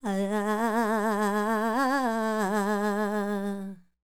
QAWALLI 08.wav